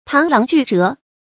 螳螂拒轍 注音： ㄊㄤˊ ㄌㄤˊ ㄐㄨˋ ㄓㄜˊ 讀音讀法： 意思解釋： 猶螳臂當車。比喻做力量做不到的事情，必然失敗。